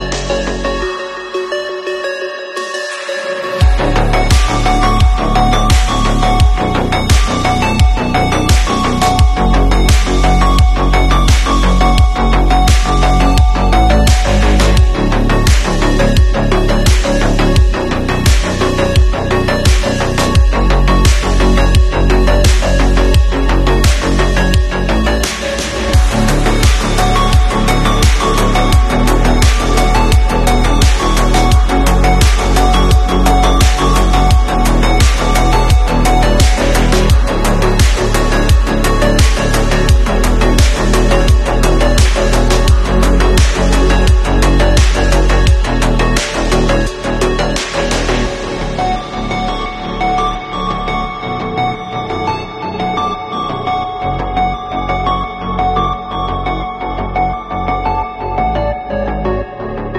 Robot Woman Becomes Ill And Sound Effects Free Download